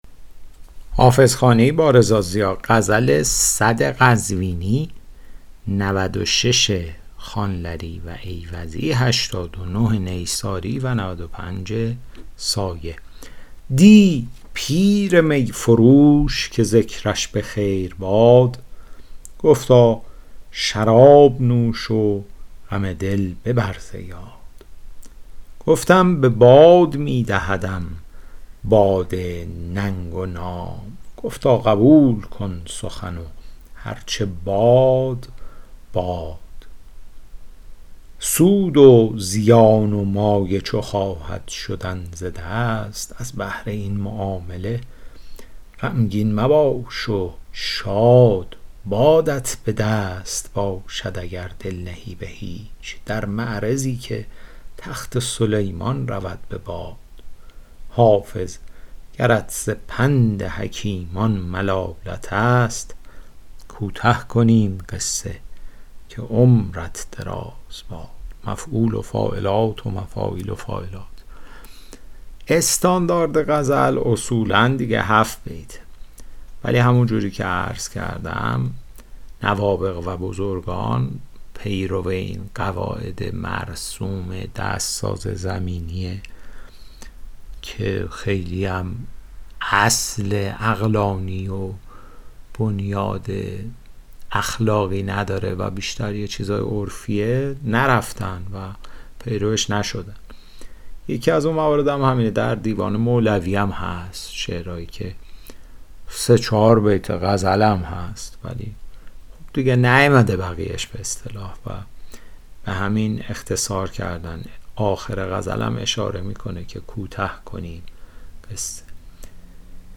شرح صوتی غزل شمارهٔ ۱۰۰